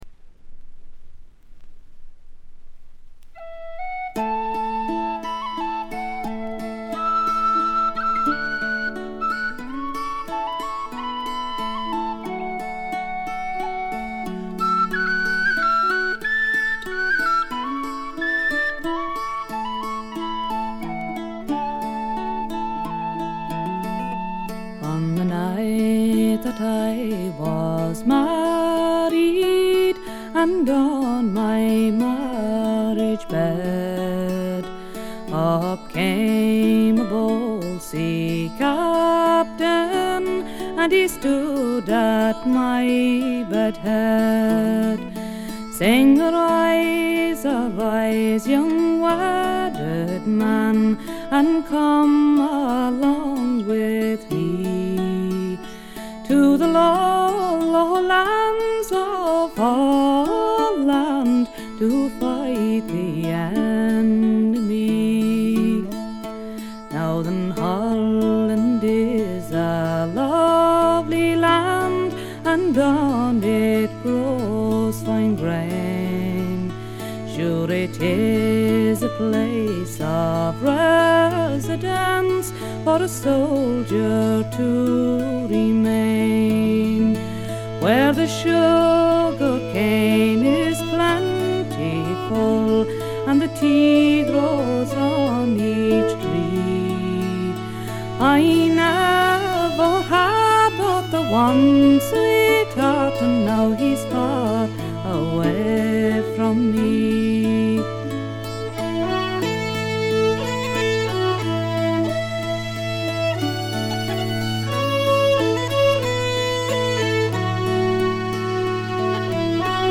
ほとんどノイズ感無し。
声自体が重層的で深いんですよね。
試聴曲は現品からの取り込み音源です。